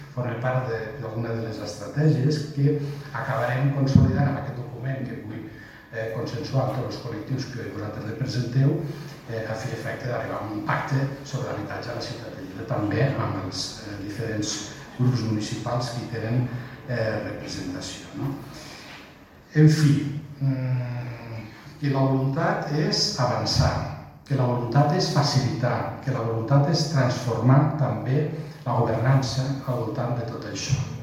Fitxers relacionats Tall de veu del paer en cap, Fèlix Larrosa, sobre la reducció dels terminis en la resolució d'expedients i l'estratègia per impulsar la construcció d’habitatge assequible a Lleida.